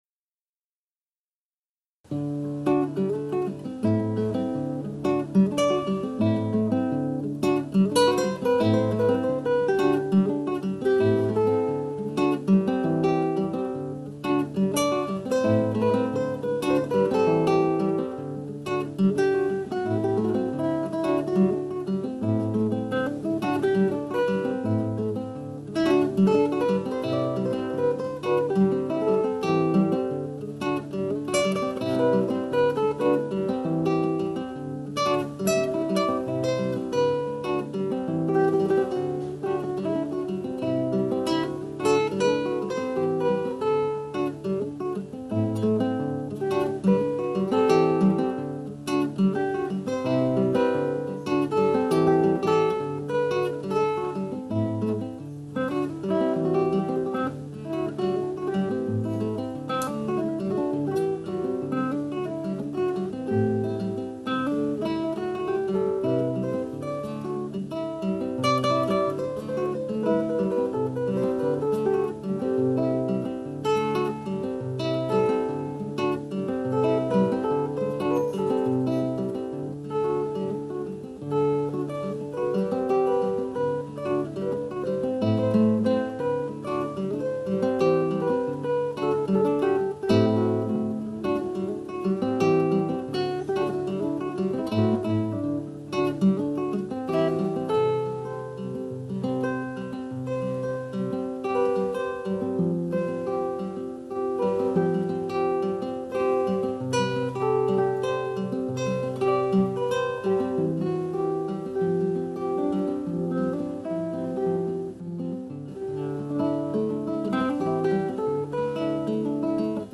Some original guitar samplers:
Water Dance (guitar)